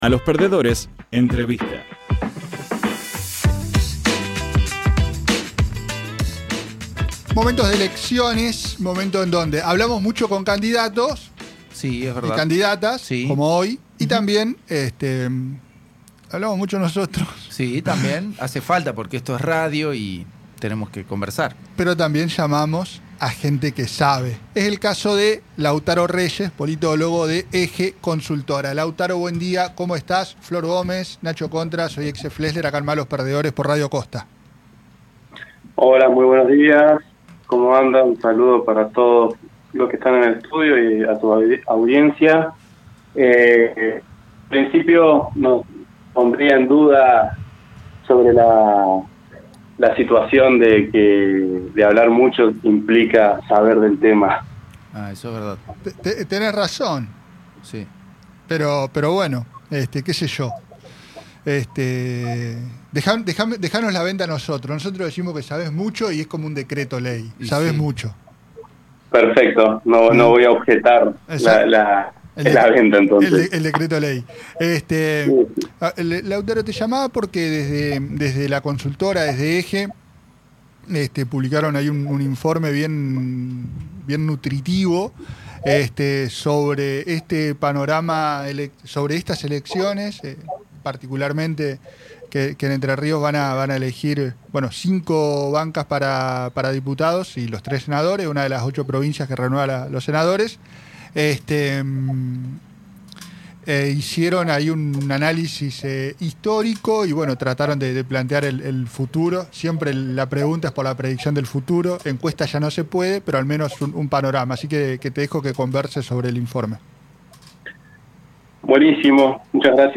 Entrevista
Radio Costa Paraná – 88.1